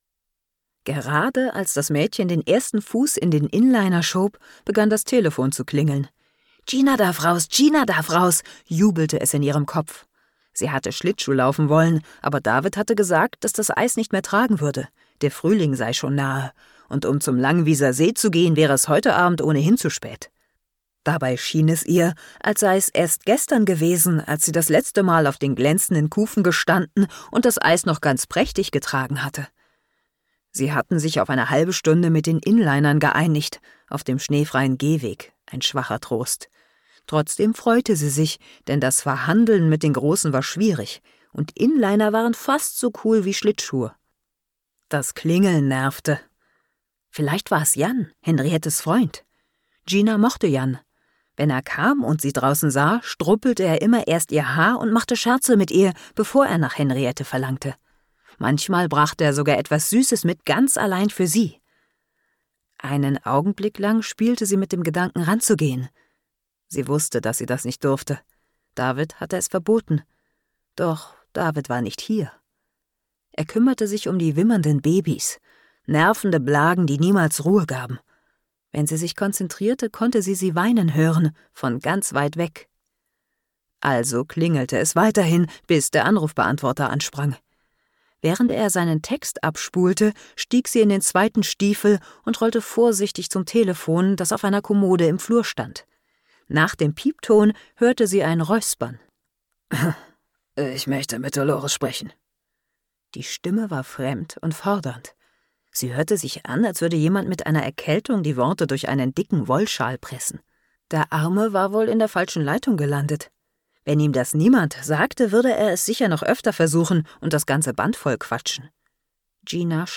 markant, sehr variabel, dunkel, sonor, souverän
Mittel plus (35-65)
Norddeutsch
Hörbuch - Ausschnitt "Scherbenkind"
Audiobook (Hörbuch)